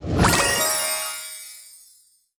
voice_huodejiangli.wav